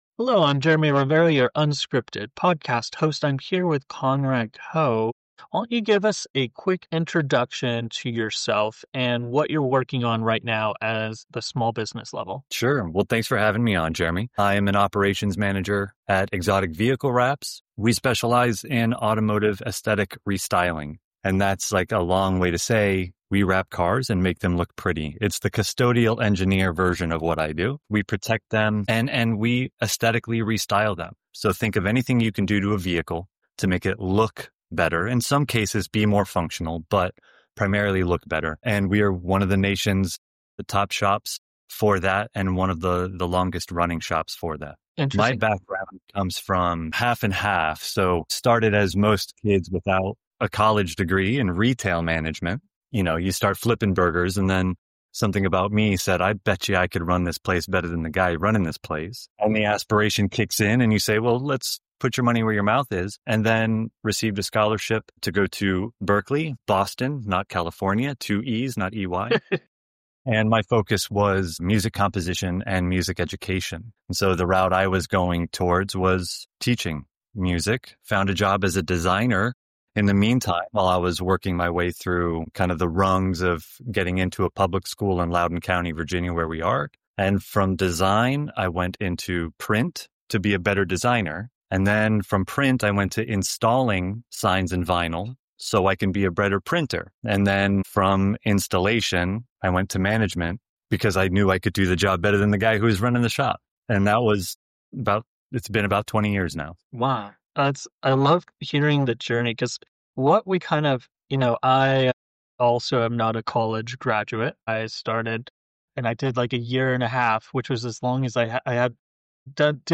having unscripted interviews small business owners, founders and creators across the United States